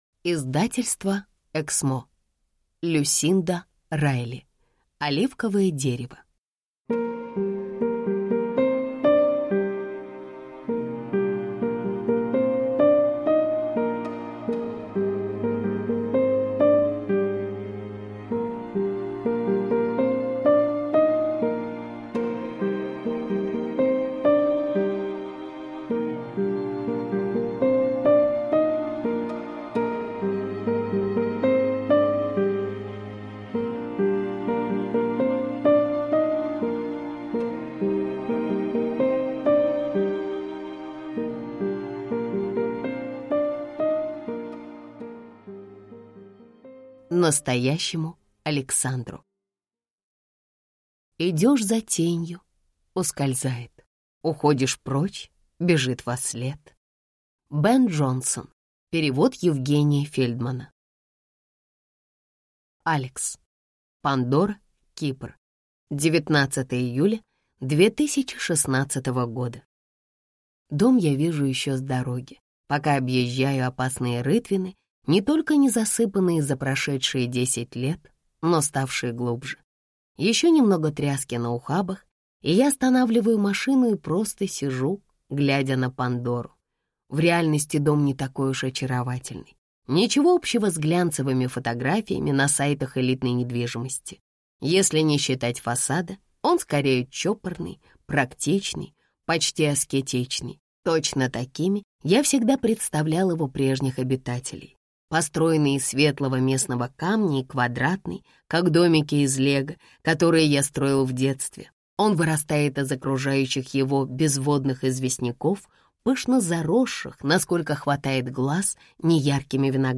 Аудиокнига Оливковое дерево | Библиотека аудиокниг
Прослушать и бесплатно скачать фрагмент аудиокниги